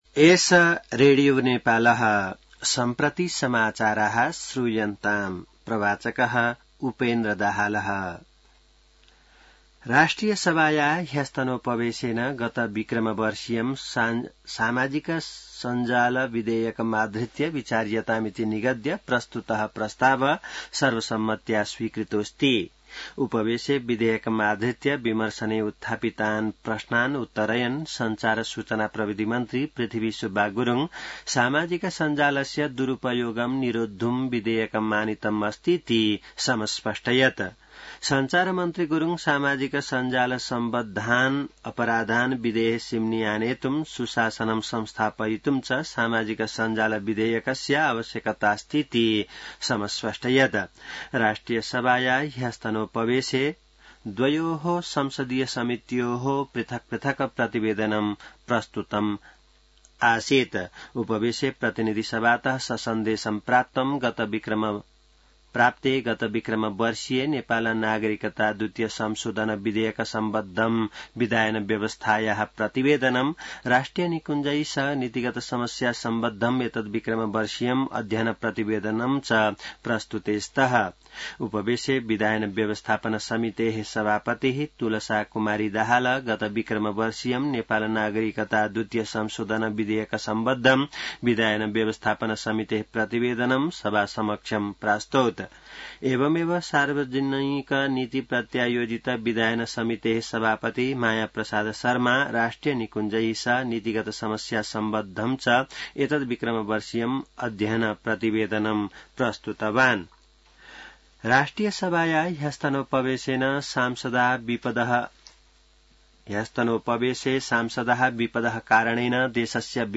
संस्कृत समाचार : ४ भदौ , २०८२